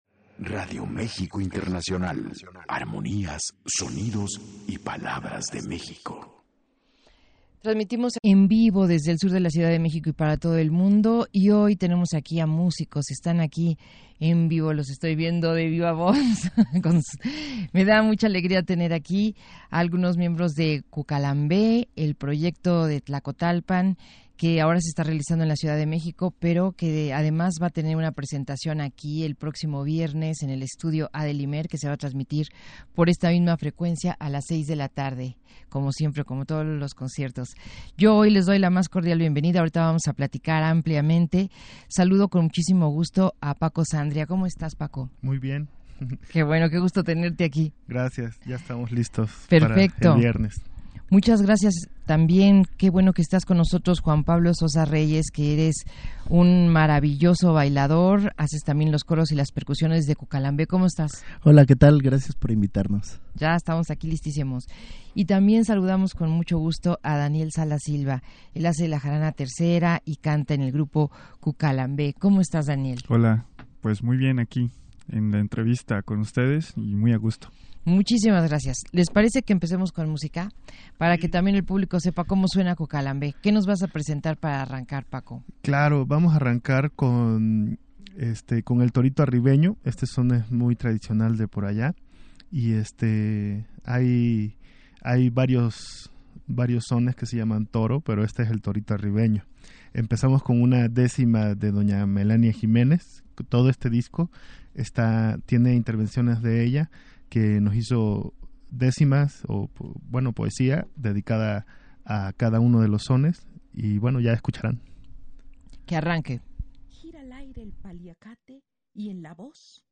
entrevista_cucalambe.mp3